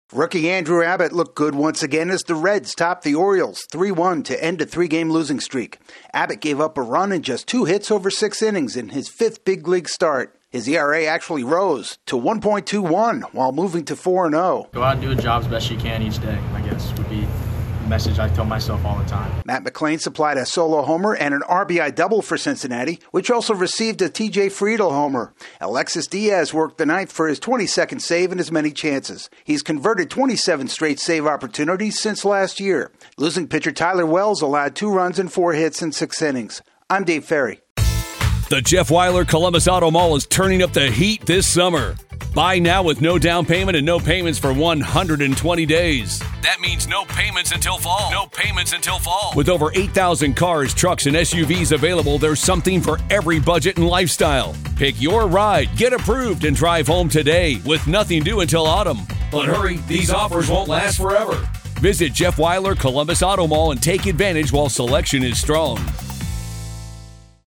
The Reds halt their skid behind their hot-shot rookie hurler. AP correspondent